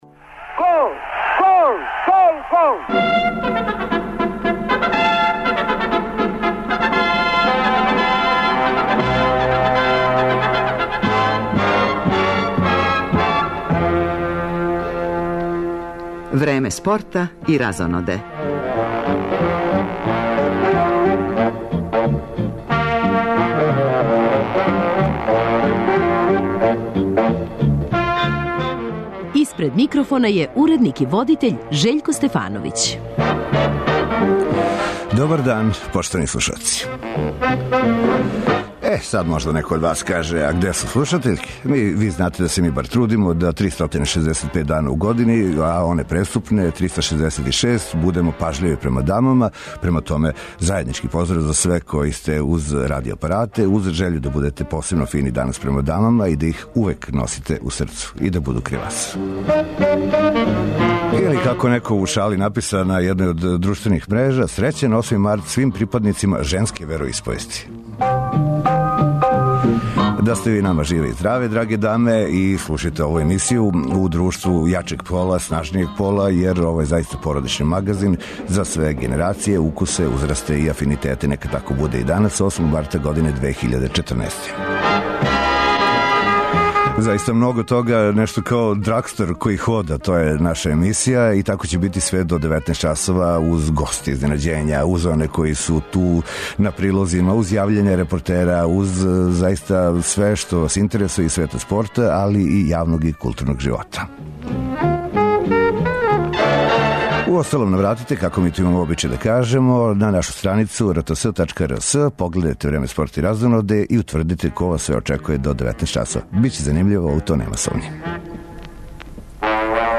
Репортери емисије данас ће се јављати са четири утакмице 18.кола Супер лиге Србије у фудбалу.